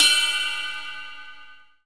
RIDE CYMBAL.wav